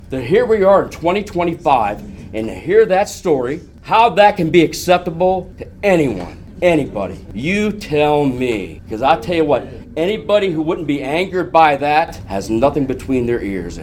At Monday’s press conference of Bel Air residents fighting against high water rates from Maryland Water Service, story after story was shared of high bills and severe water conservation measures.